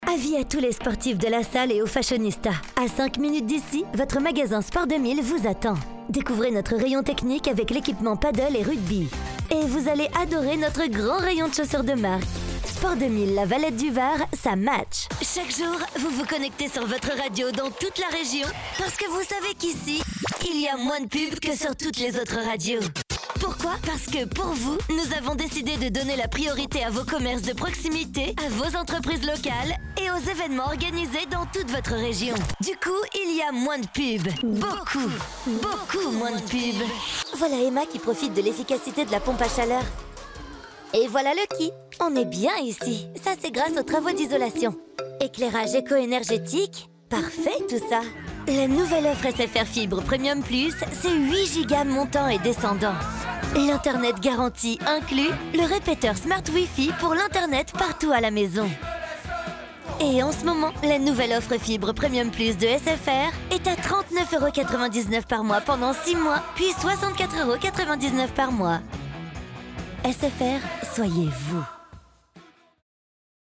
Voix off féminine pour vos pub radio et tv, billboards, mais aussi doublage et voice over, j'enregistre ma voix depuis mon home studio professionnel et livre ma voix sous 24h
Voix off féminine pour publicités radio et tv
Voici quelques exemples de voix de publicité tv et radio que j’ai récemment réalisées :